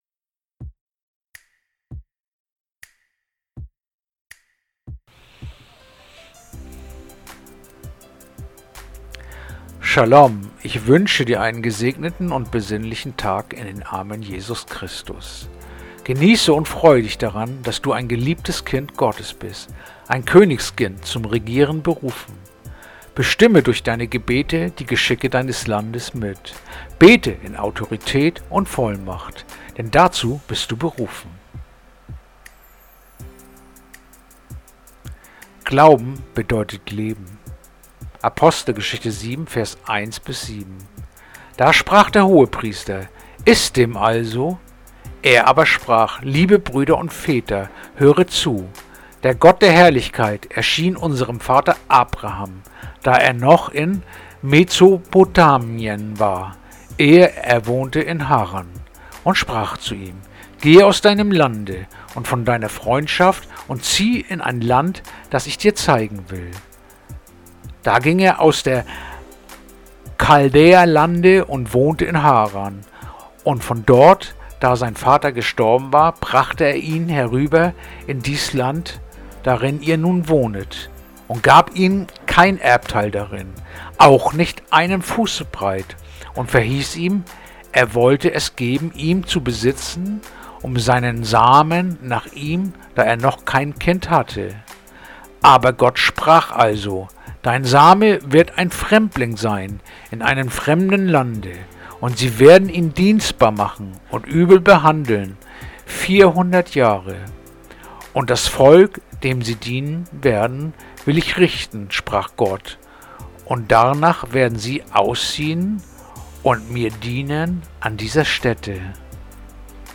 heutige akustische Andacht
Andacht-vom-23-August-Apostelgeschichte-7-1-7.mp3